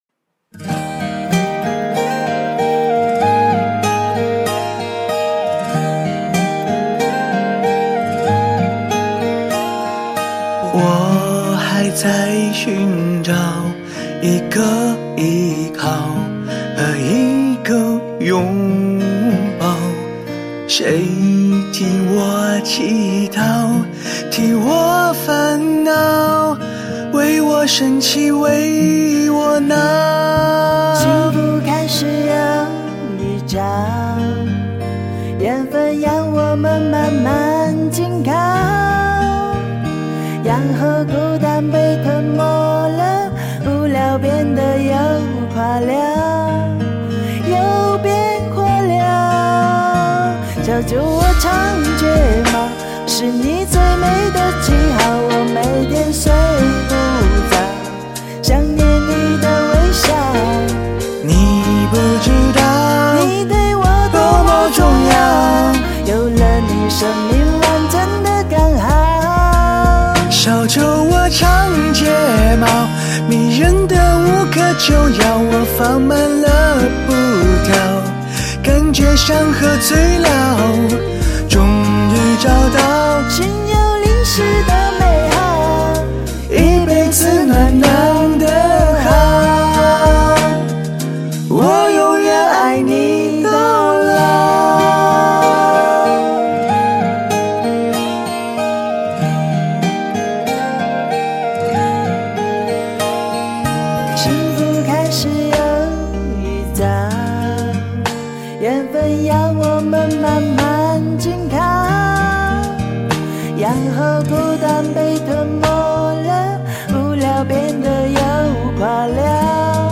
尤其跟大帅哥合唱。
天作之合，默契！好听！
两个声音都好听，配合默契